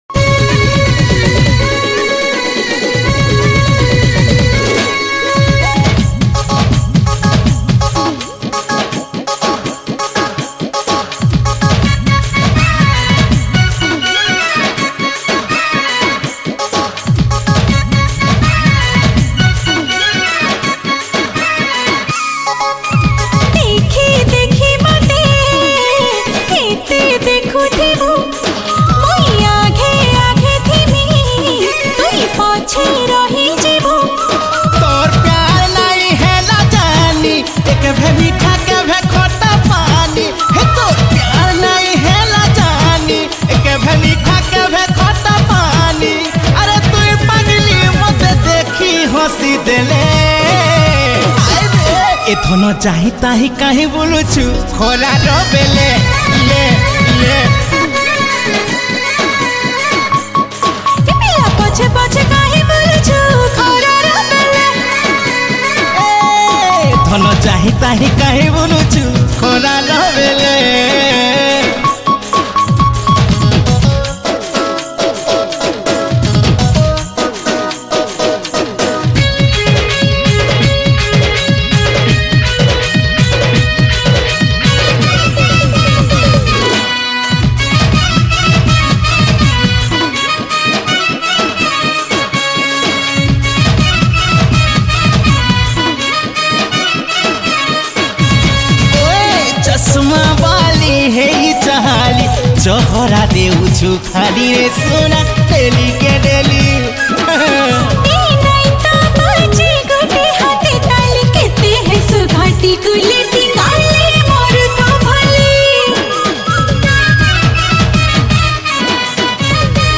Category : Sambapuri Single Song 2022